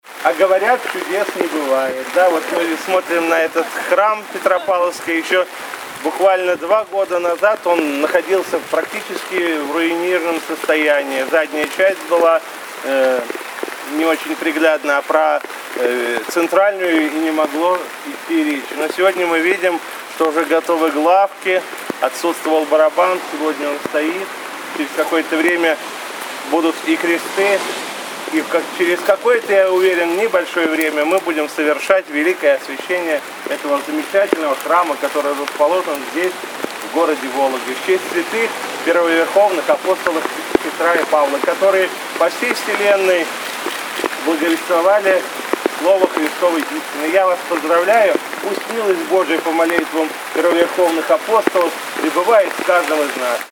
Поэтому назначенный на субботний день чин освящения куполов для храма Петра и Павла переносить на другой день из-за начавшегося дождя не стали.
По завершении чина освящения митрополит Игнатий в своем приветственном слове к прихожанам Петропавловского храма выразил уверенность, что великое освящение храма, воссозданного из небытия, обязательно произойдет через очень короткий промежуток времени.
Слово после освящения куполов храма Петра и Павла г.Вологды